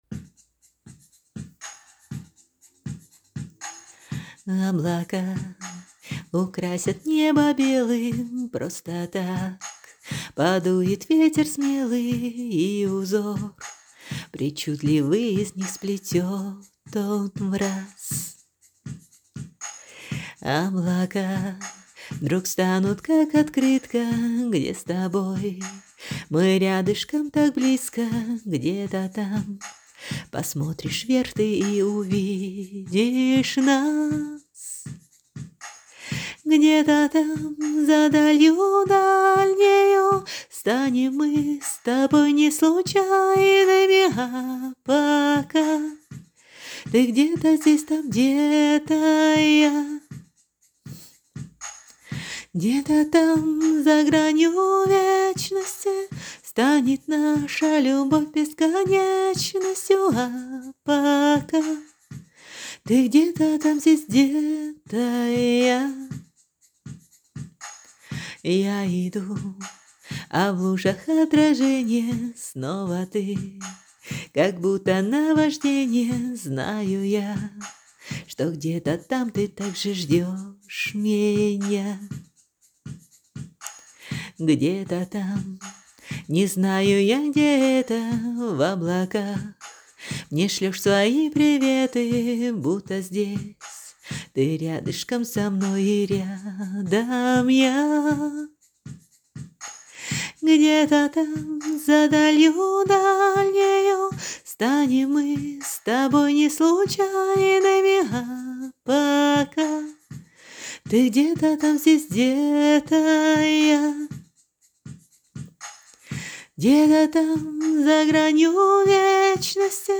Для песен я напеваю под ударники на киборде)